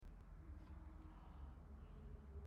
silence.mp3